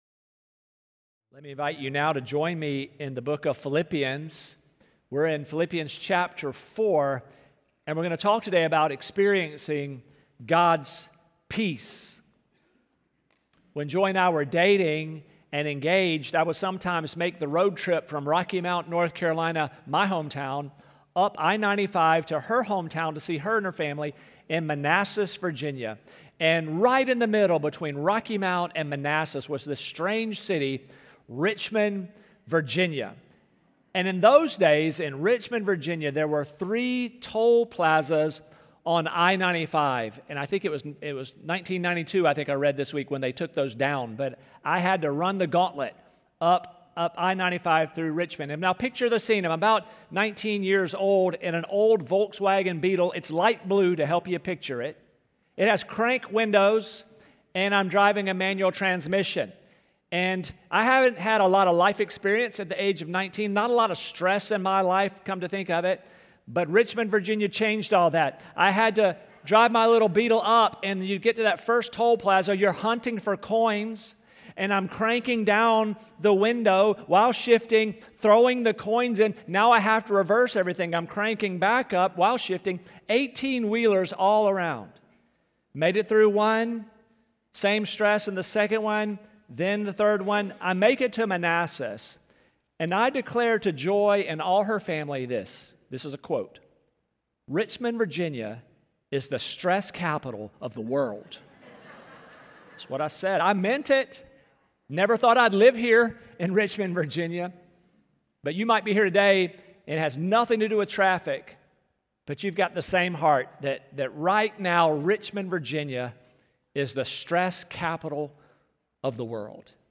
Sermons | Staples Mill Road Baptist Church